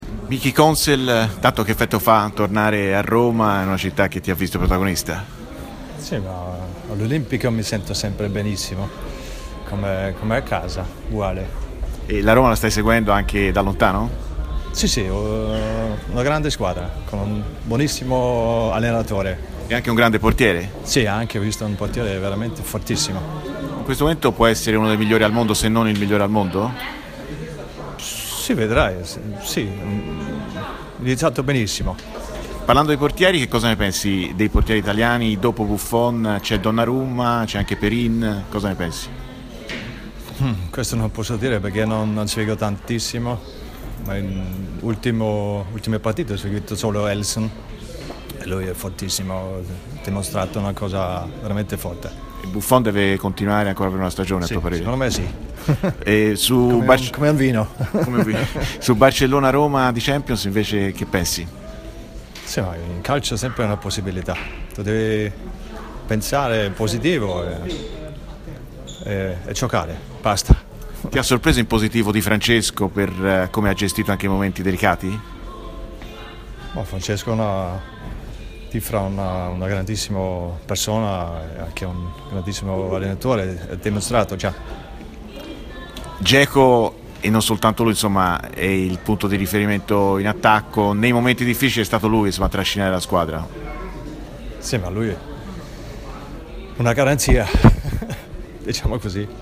Michael Konsel, portiere austriaco e in Italia con la maglia della Roma.
Michael Konsel, a margine della Partita Mundial, al microfono di RMC Sport © registrazione di TMW Radio